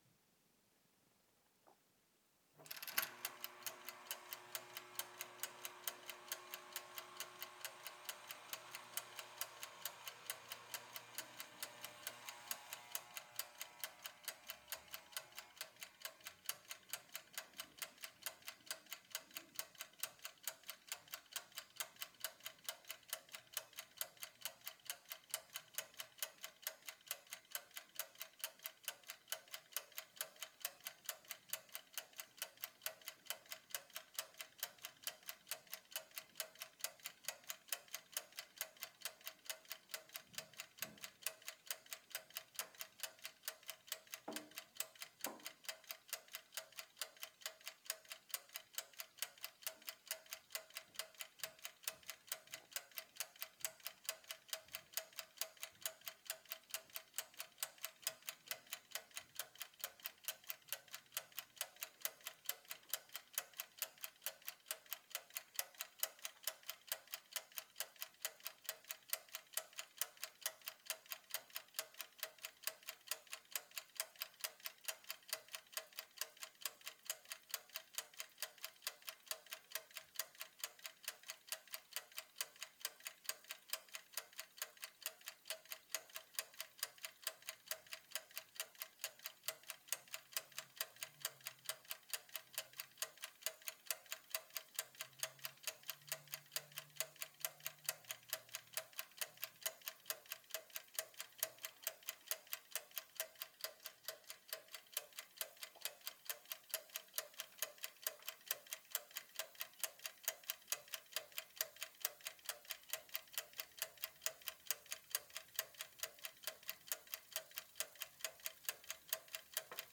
Toaster oven, ticking timer and bell